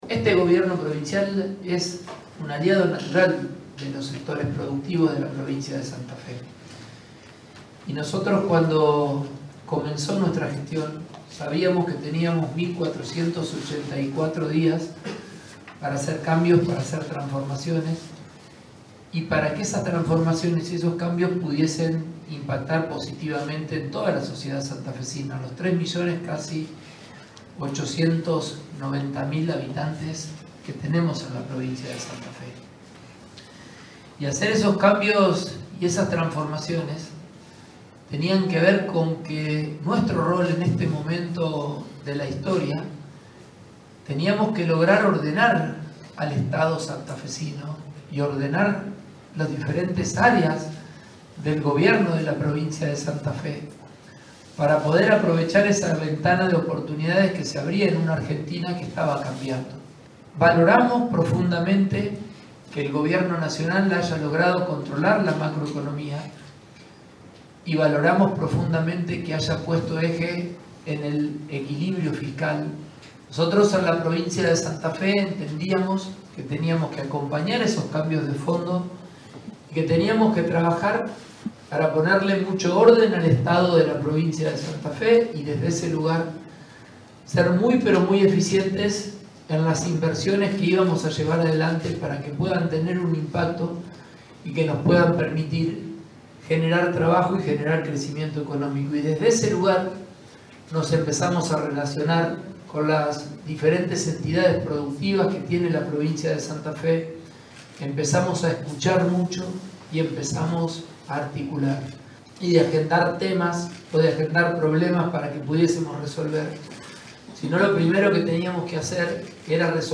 El gobernador participó del brindis de fin de año de la Bolsa de Comercio de Santa Fe. Allí mencionó las reformas e inversiones encaradas por el Estado provincial para potenciar al sector productivo.
Gobernador Maximiliano Pullaro